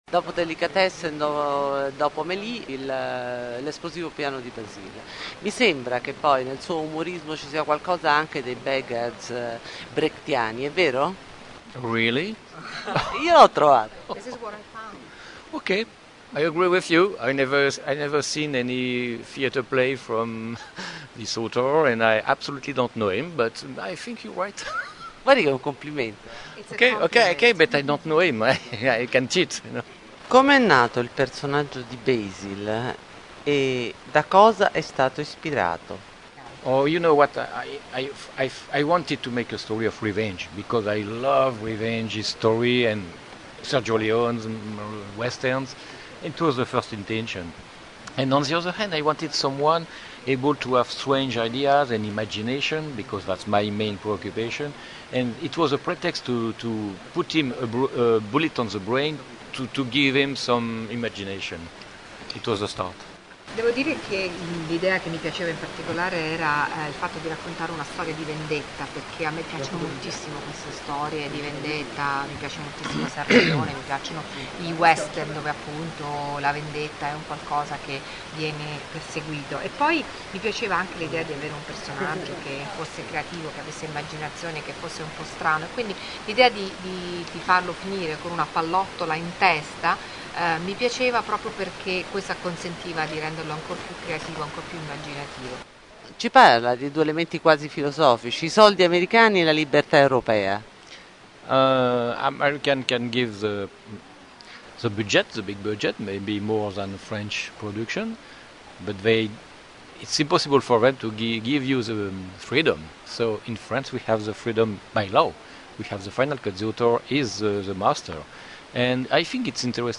Intervista_Jean-Pierre_Jeunet_Regista_L_esplosivo_piano_di_Bazil.MP3